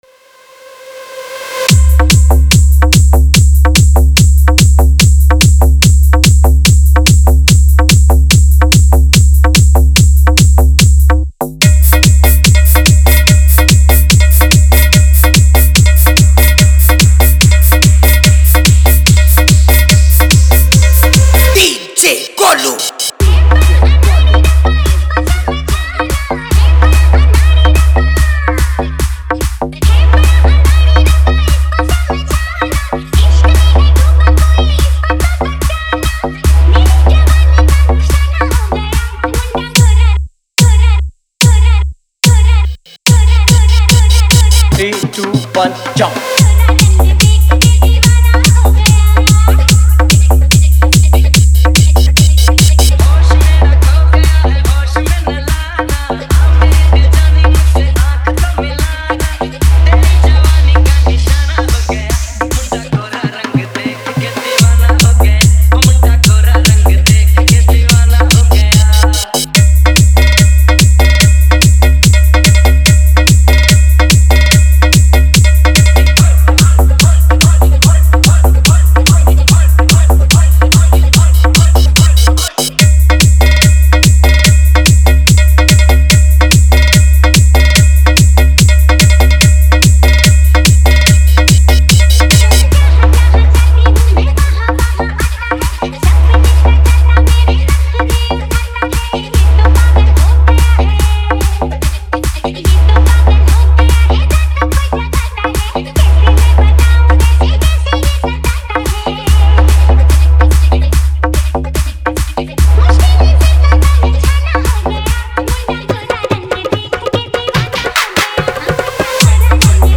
Hindi Dj Remix Songs